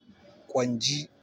Captions English Pronunciation of Hausa word